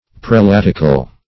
Meaning of prelatical. prelatical synonyms, pronunciation, spelling and more from Free Dictionary.
Search Result for " prelatical" : The Collaborative International Dictionary of English v.0.48: Prelatic \Pre*lat"ic\, Prelatical \Pre*lat"ic*al\, a. Of or pertaining to prelates or prelacy; as, prelatical authority.